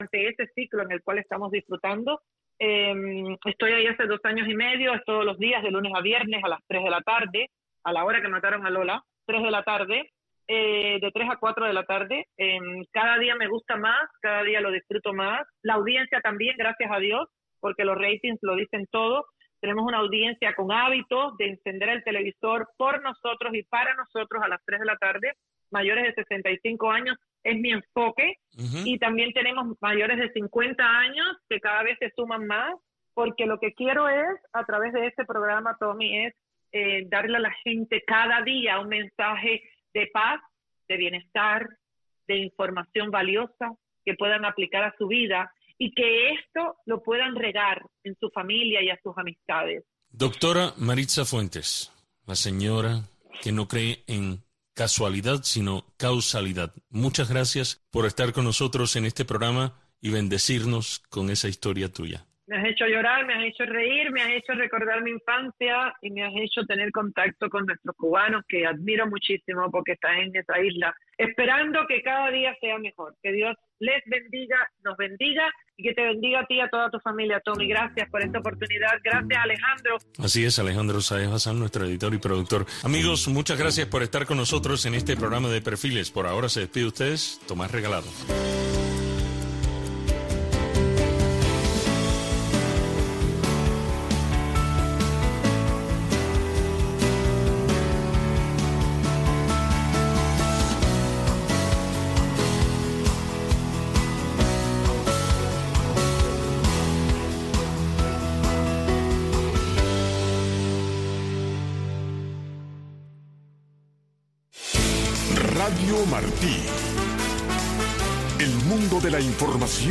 Deportes con los Grandes. Un programa de Radio Marti, especializado en entrevistas, comentarios, análisis de los Grandes del deporte.